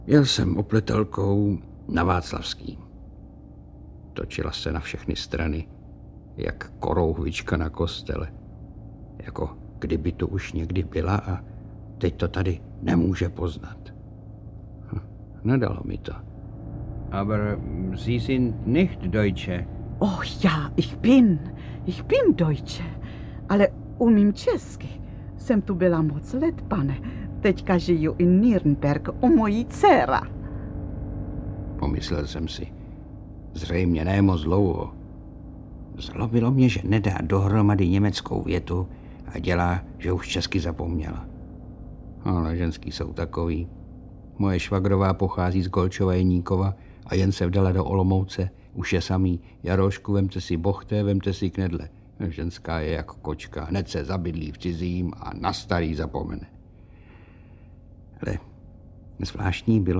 Audiobook
Audiobooks » Poetry, Classic Works